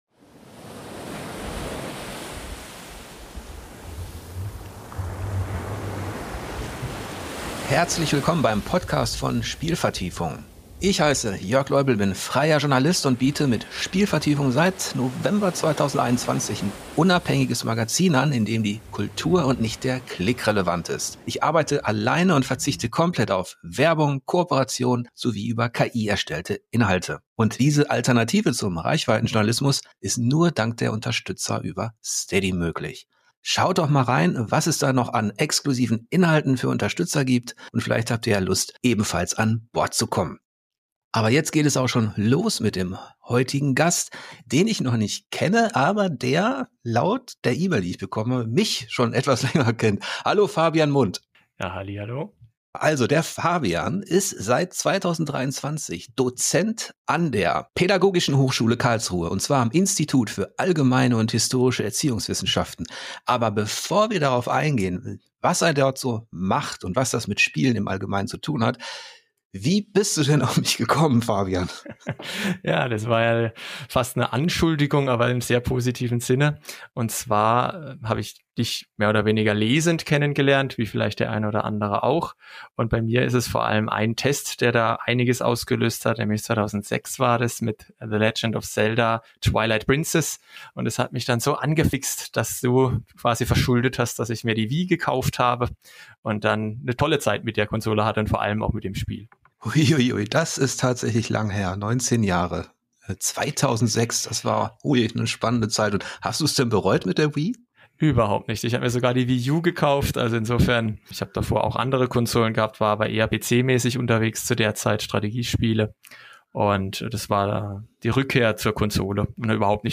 Ich bitte die gelegentlichen Tonprobleme zu entschuldigen; im Schnitt ließen sich leider nicht alle entfernen.